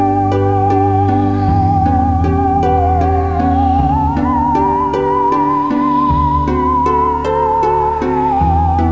4:13 - Adagio - 14 Aug 2003